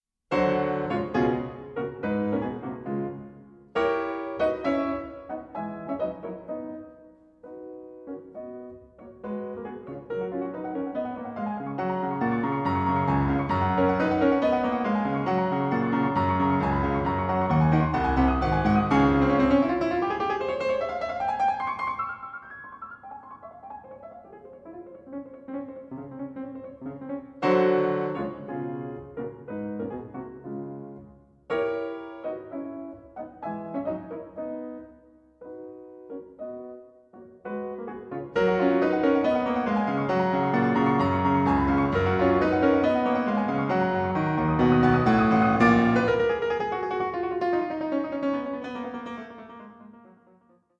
The piece opens with one of Beethoven's more unusual (and lengthy) main themes, which features enough repetition to drive any pianist mad.
When treated orchestrally, the passage shapes itself.
PIANO MUSIC